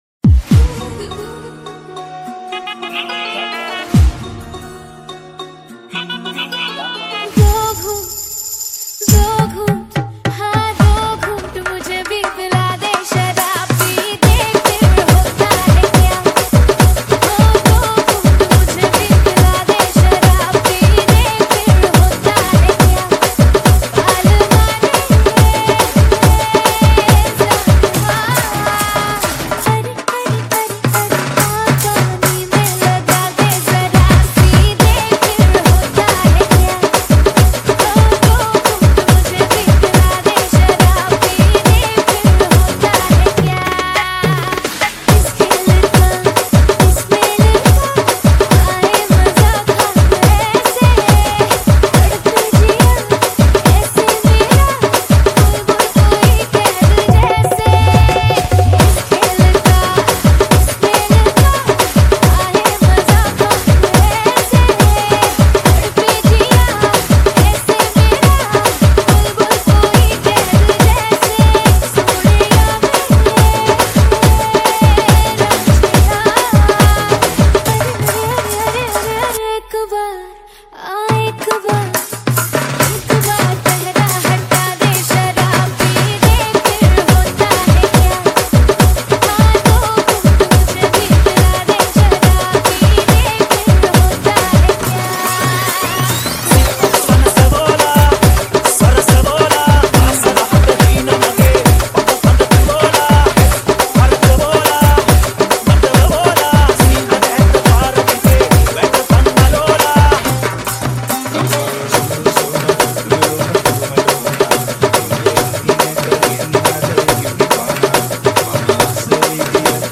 2025 New Sinhala DJ Nonstop Kawadi n Dholki